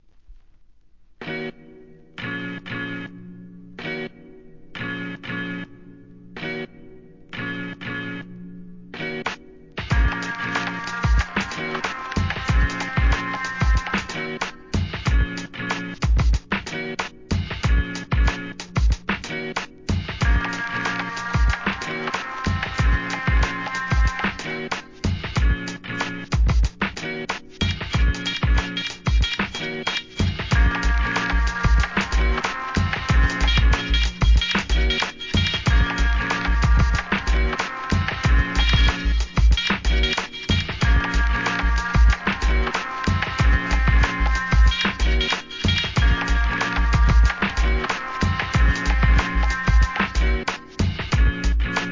1. HIP HOP/R&B
名作アブストラクト・ブレイクビーツ！